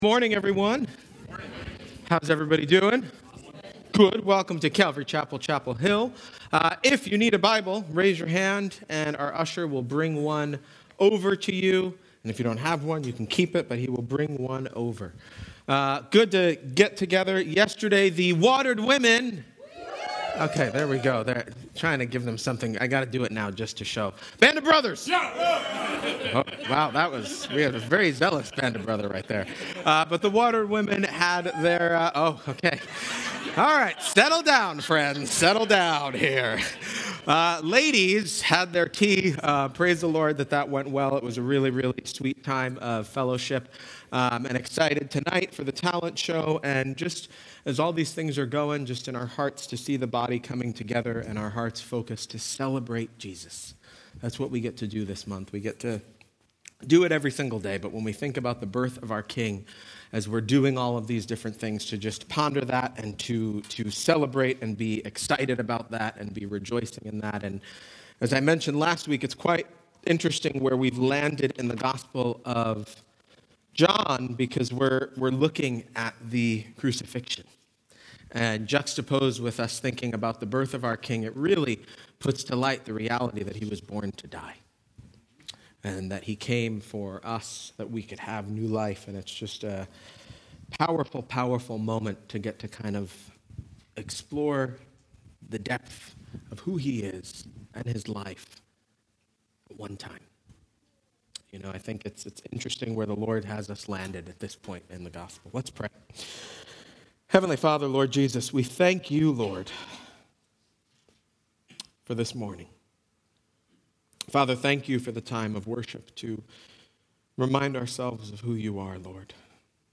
An Eternal Exhale (forgive some audio issues at start).